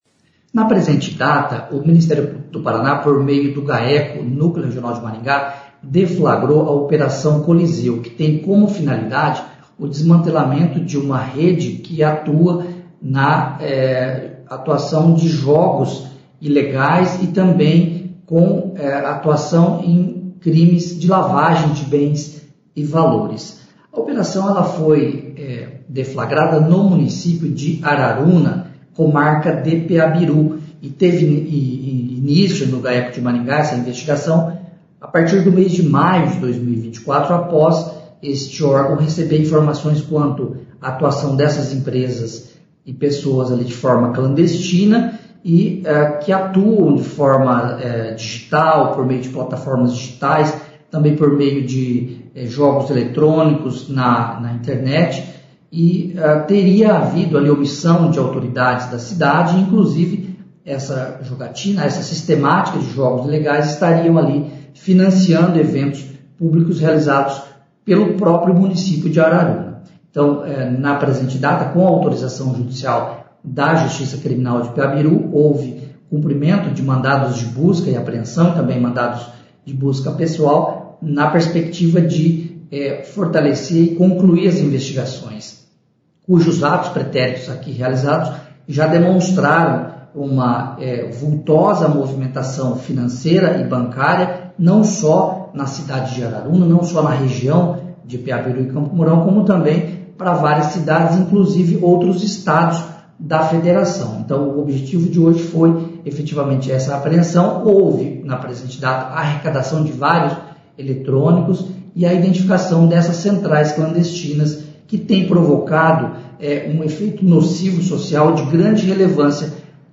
Ouça o que diz o promotor de Justiça Marcelo Alessandro Gobbato: